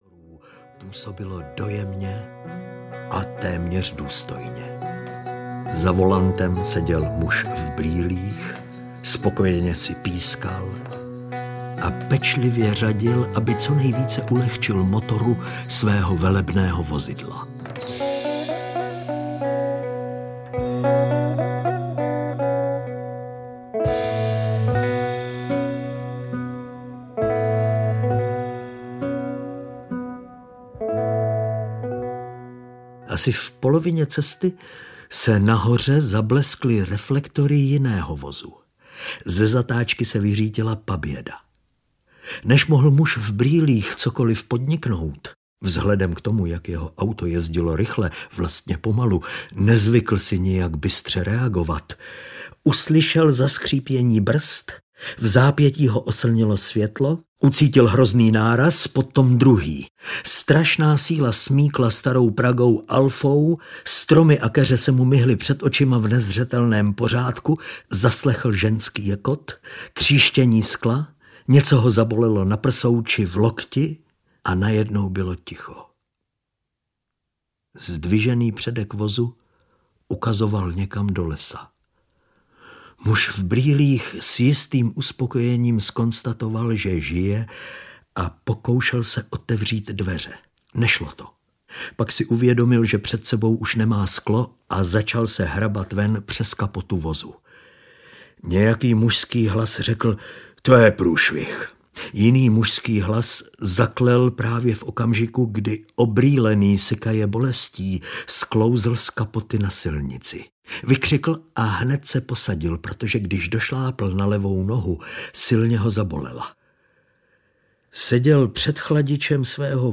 Poklad byzantského kupce audiokniha
Ukázka z knihy